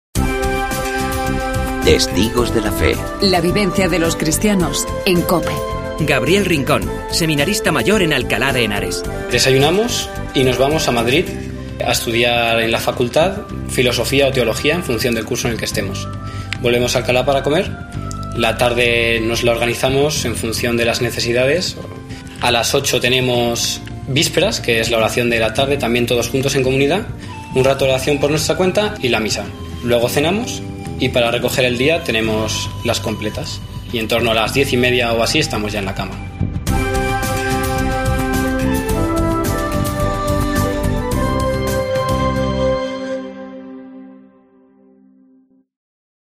Testimonio de un sacerdote: "Tenía que seguir a Dios, pero me preguntaba cómo"